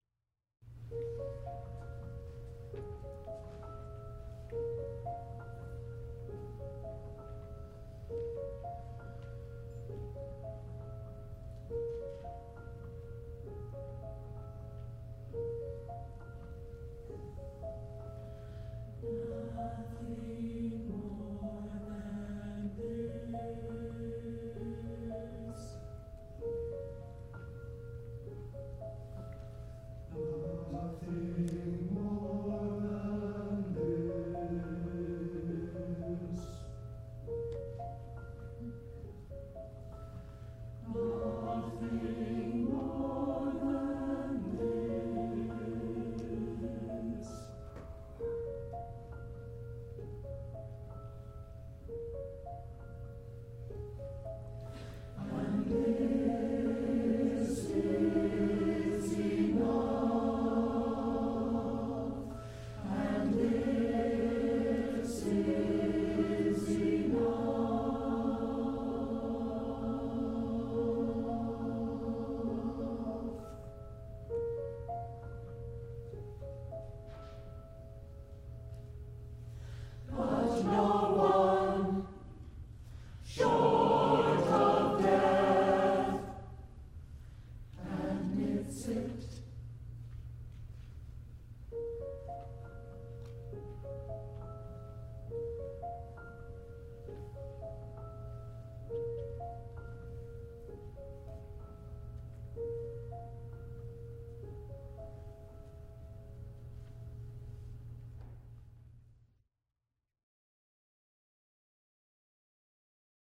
Eight Zen-like poems for SATB (and piano in some of them.)